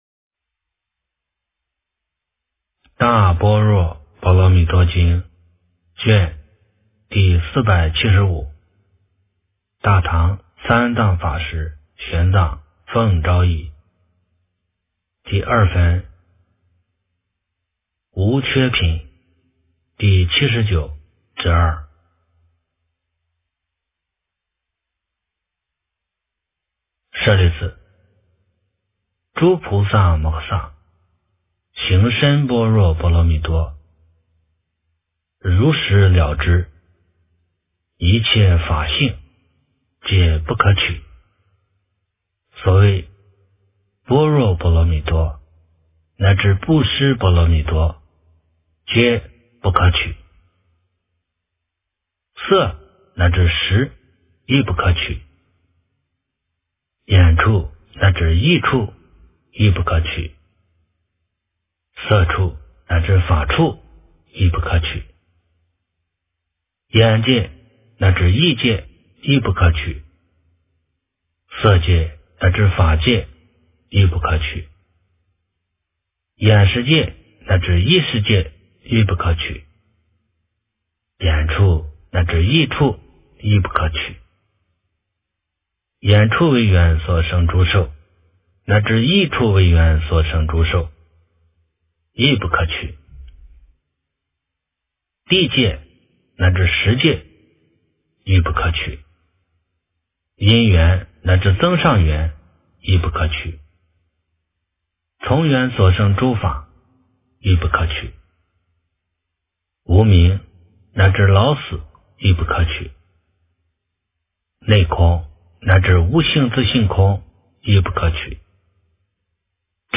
诵经
佛音 诵经 佛教音乐 返回列表 上一篇： 早课-楞严咒+大悲咒 下一篇： 大般若波罗蜜多经第480卷 相关文章 职场01奉献工作也是菩萨精神--佛音大家唱 职场01奉献工作也是菩萨精神--佛音大家唱...